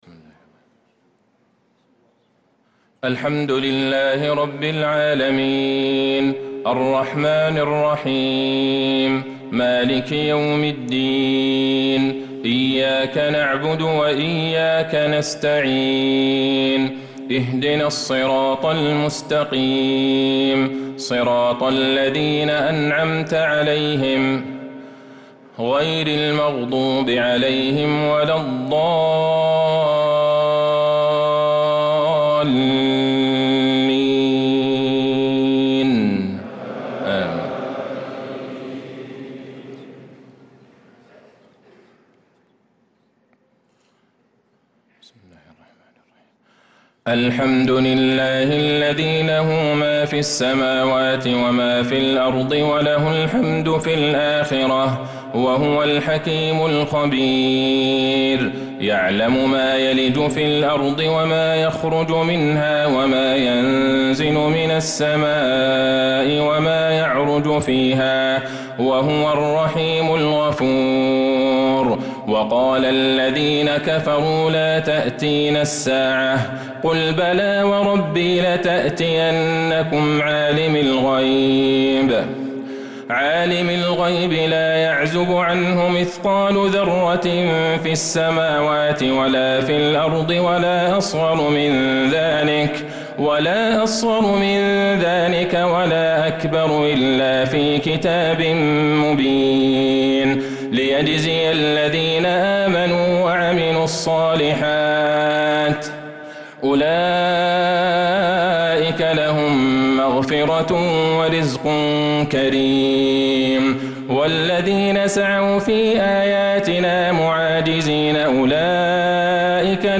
صلاة العشاء للقارئ عبدالله البعيجان 17 رجب 1446 هـ